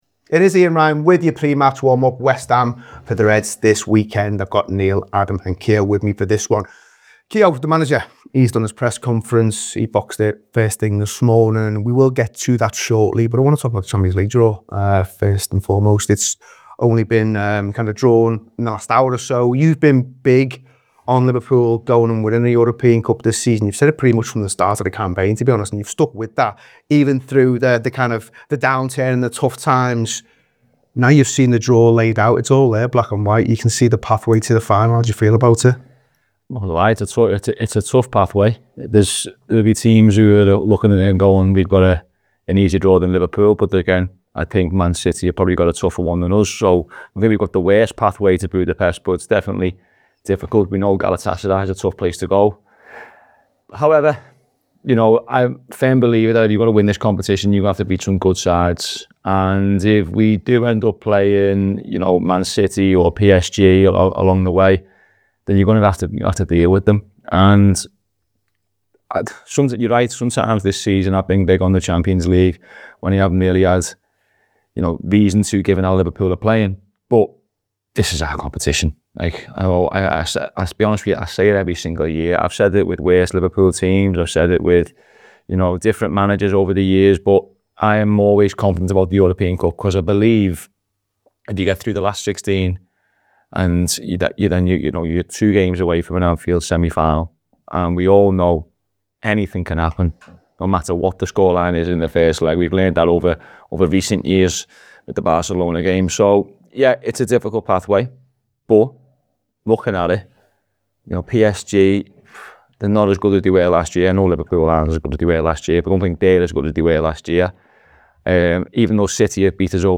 The Anfield Wrap’s pre match show ahead of Liverpool’s clash with West Ham United at Anfield as the Reds try and maintain pressure on the teams around them chasing Champions League football.